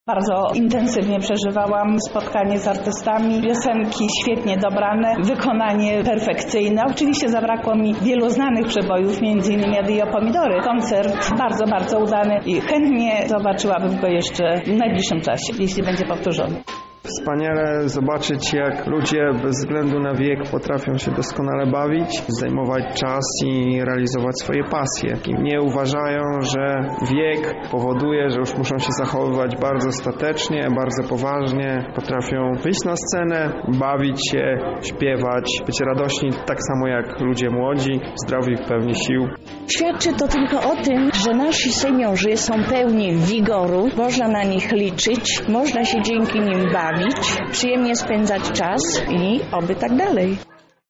Do śpiewania najbardziej rozpoznawalnych kawałków dołączała publiczność.